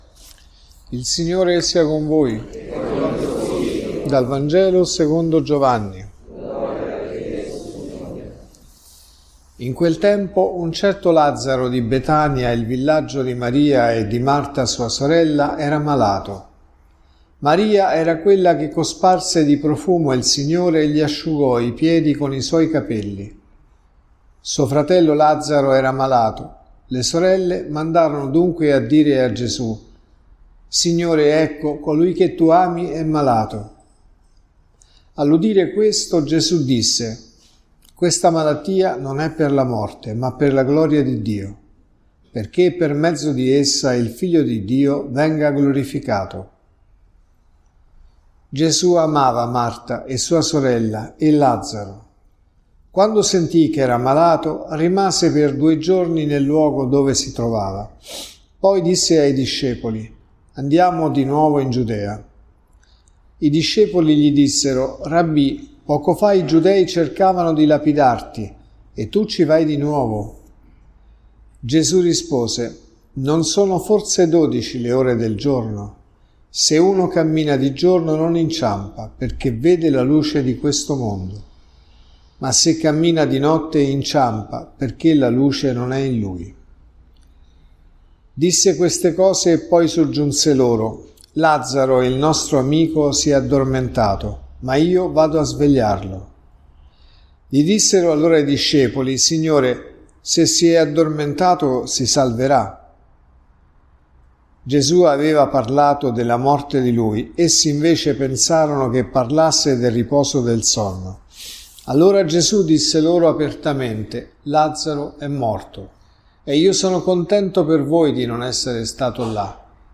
Omelie